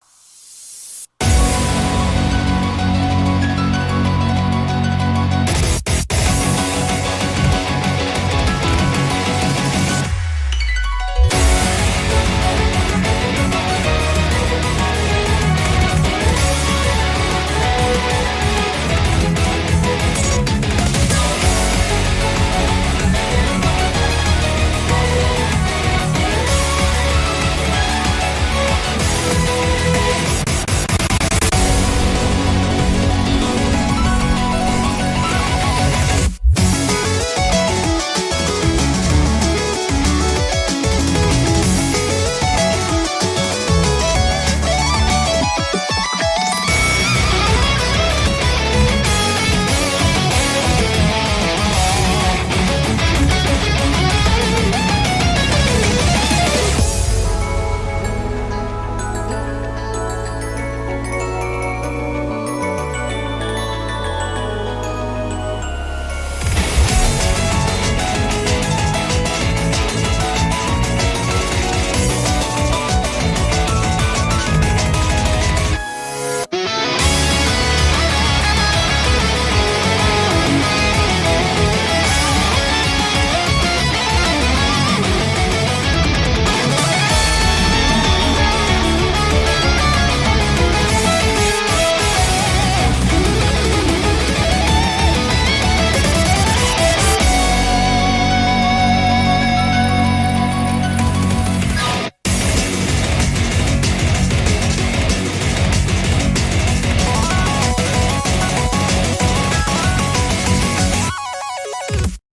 BPM190
MP3 QualityMusic Cut